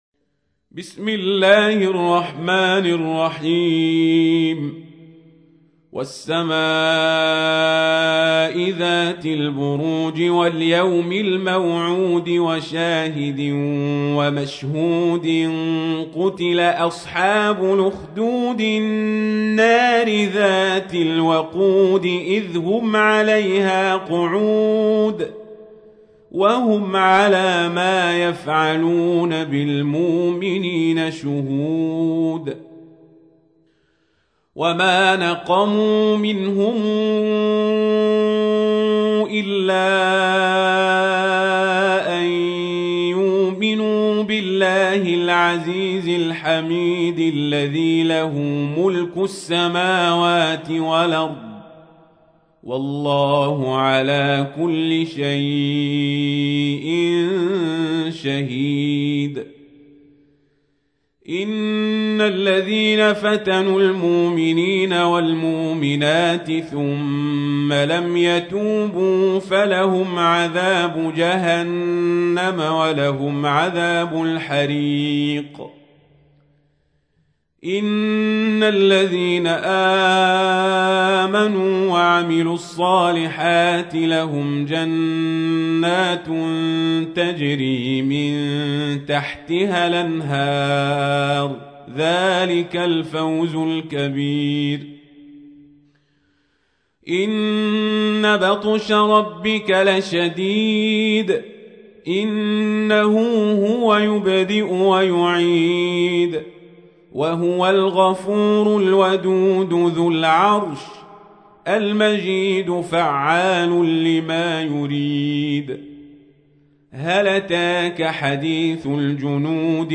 تحميل : 85. سورة البروج / القارئ القزابري / القرآن الكريم / موقع يا حسين